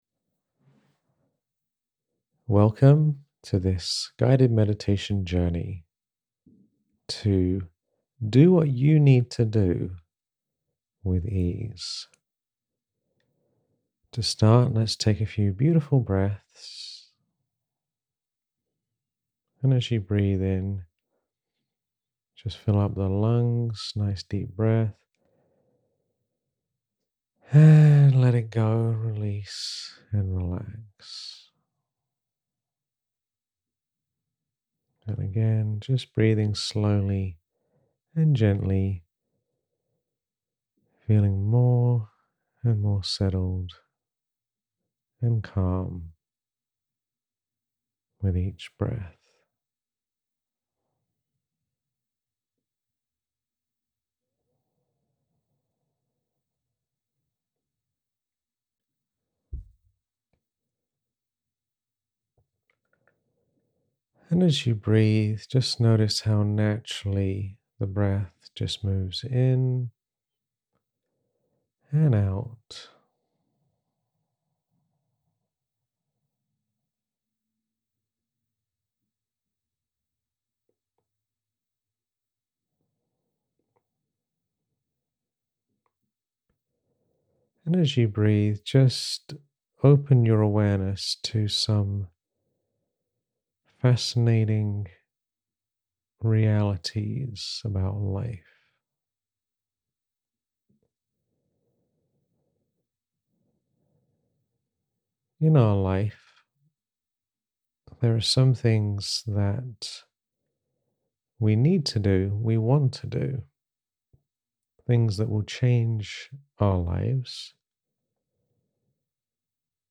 [Meditation] Rewire your brain for new habits